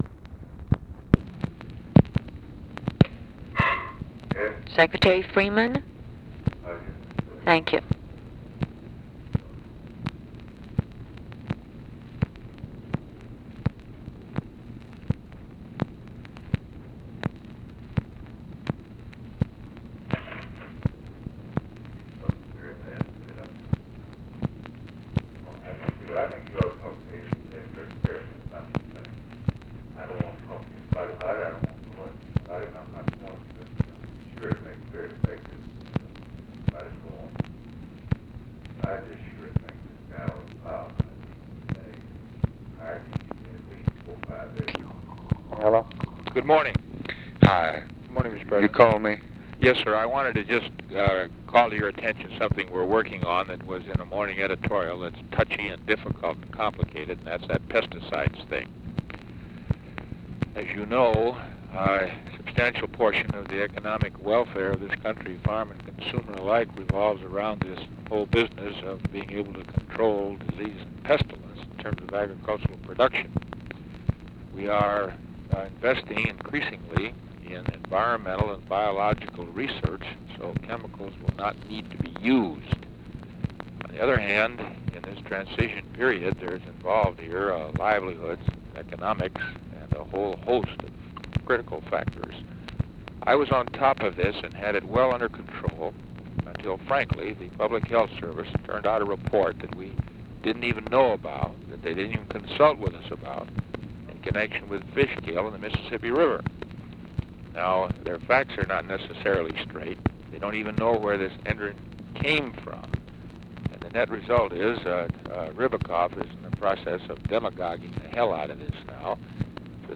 Conversation with ORVILLE FREEMAN and OFFICE CONVERSATION, April 13, 1964